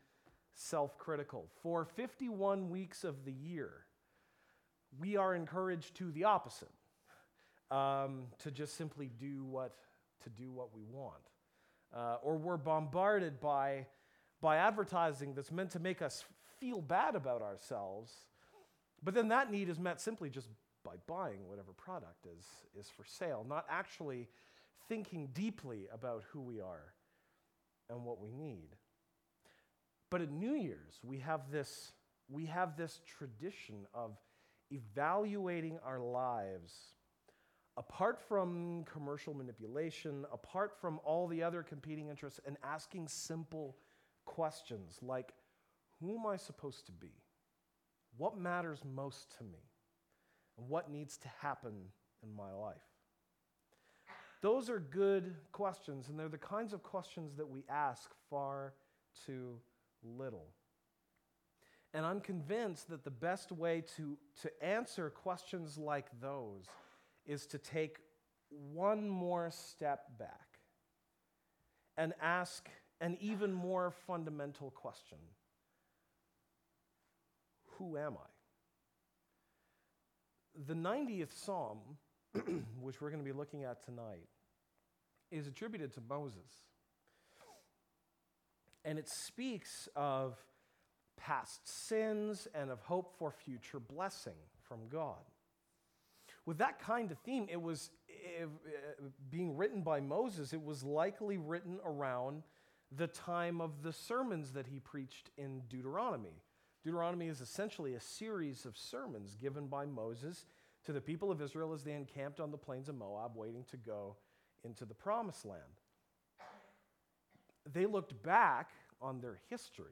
December 31, 2015 (Sunday Morning)
Archived Sermons